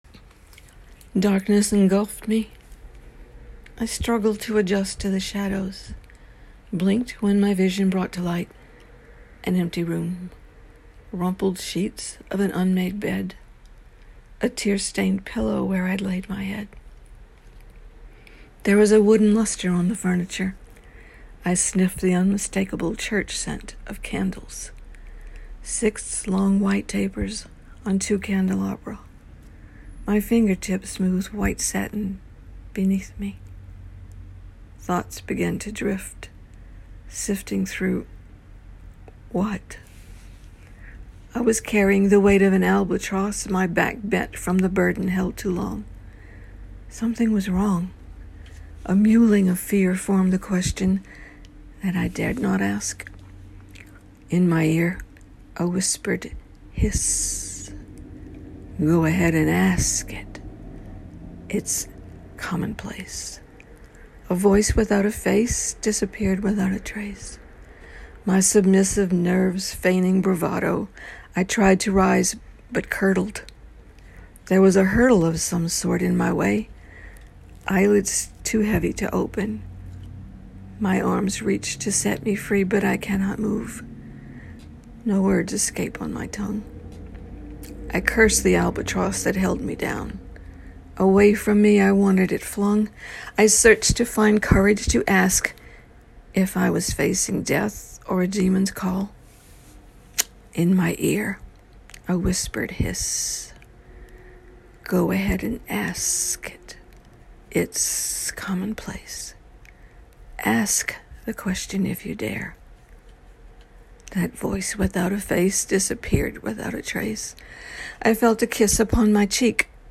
Gosh so good to listen to a real human voice that is so powerful and soulful!
I enjoyed reading this this morning and listening to your sweet elegant voice!